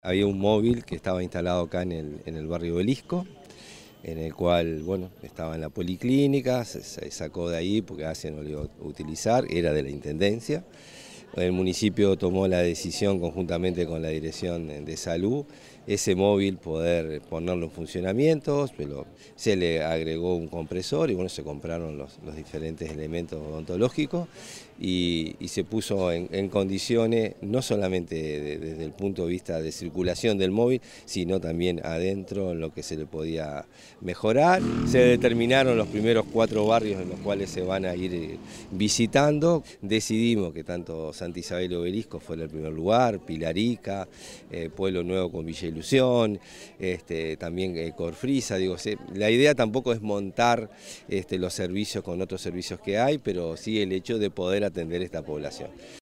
Alcalde del Municipio de Las Piedras - Gustavo González
alcalde_de_las_piedras_gustavo_gonzalez.mp3